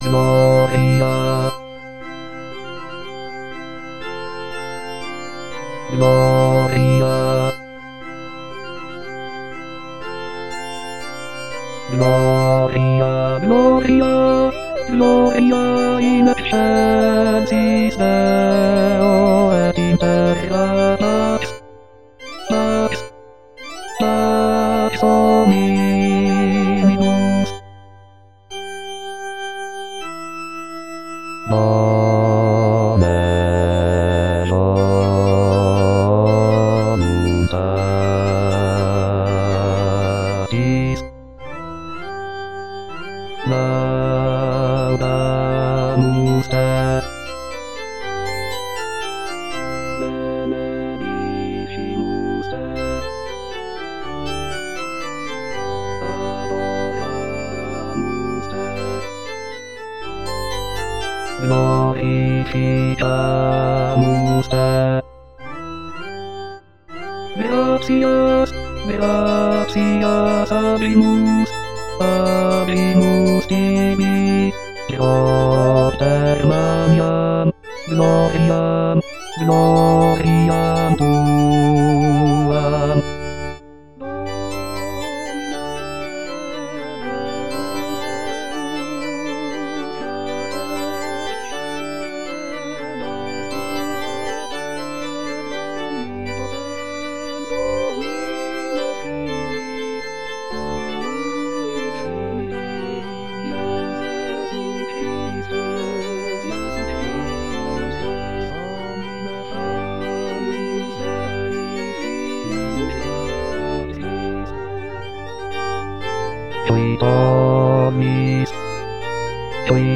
Les aides traditionnelles avec voix de synthèse
Gloria-Basse
Gloria-Basse.mp3